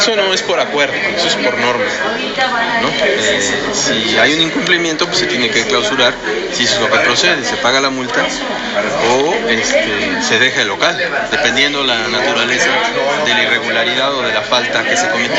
En entrevista, el funcionario municipal recordó, al ser cuestionado con relación a la inversión que se destinará para la remodelación de mercados municipales en el presente año, que la cifra depende del banco de proyectos que se presentó, por tal motivo no existe un presupuesto específico aunque la propuesta inicial fue de 30 millones de pesos; procedimiento que se trabaja con la Secretaría de Infraestructura para proyectarla.